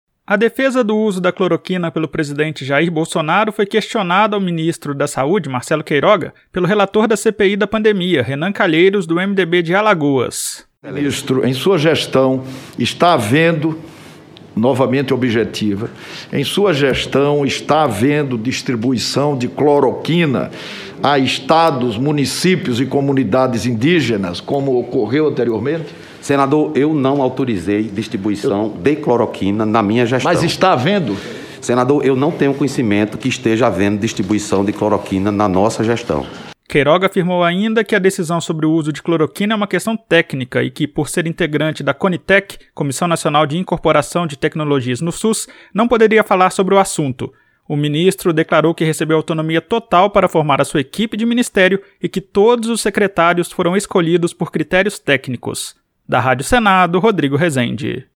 A defesa do uso da cloroquina pelo presidente Jair Bolsonaro foi questionada ao ministro da Saúde, Marcelo Queiroga, pelo relator da CPI da Pandemia, Renan Calheiros, do MDB de Alagoas. Queiroga afirmou que a decisão sobre o uso de cloroquina é uma questão técnica, e que por ser integrante da Comissão Nacional de Incorporação de Tecnologias no SUS, não poderia falar sobre o assunto.